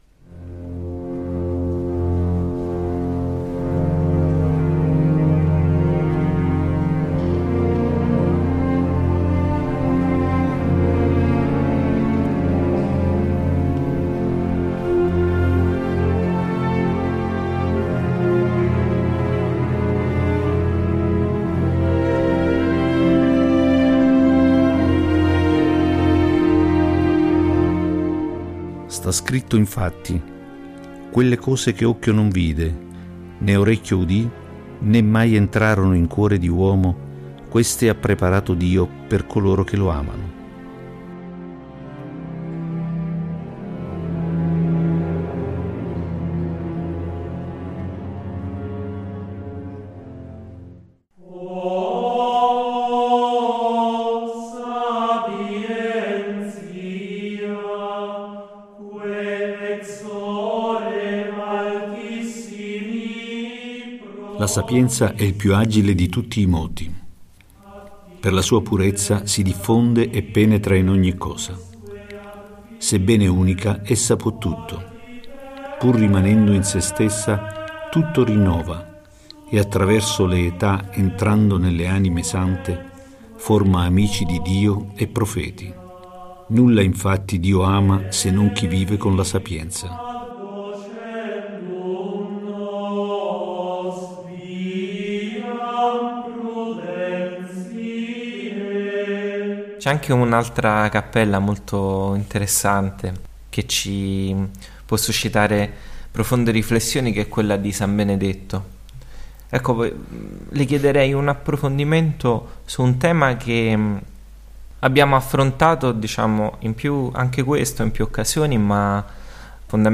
CIO-CHE-OCCHIO-NON-VIDE-PUNTATA-13-CON-EFFETTI.mp3